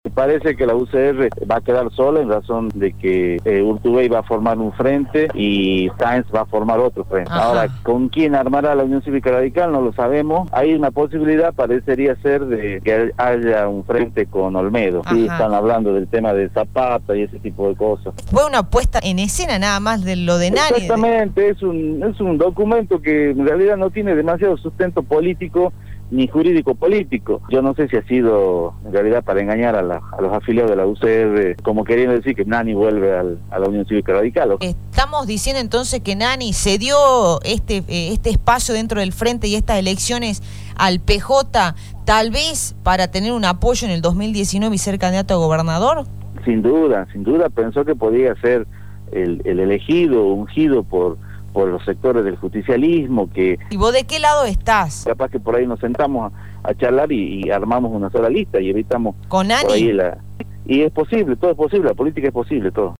El Diputado Provincial Humberto Alejandro Vazquez en diálogo con Radio Dinamo se refirió al lugar que ocupará la UCR en Salta en las PASO.